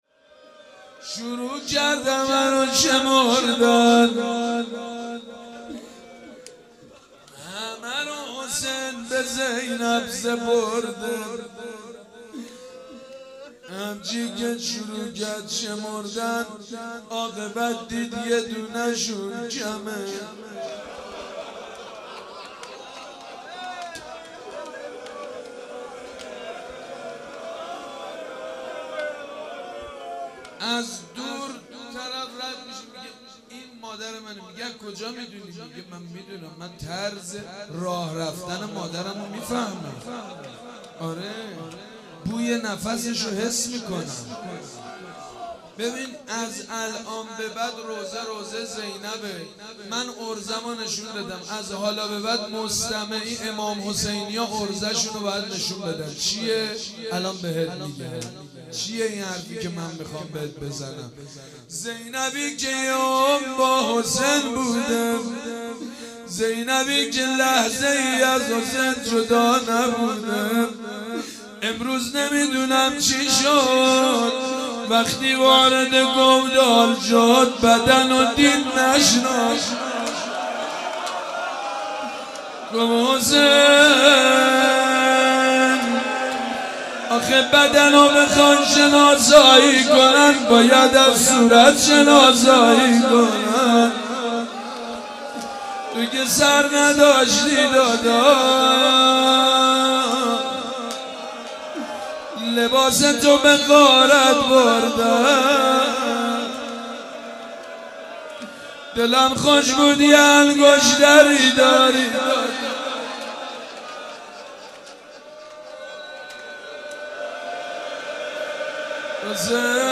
روضه سیدمجید بنی‌فاطمه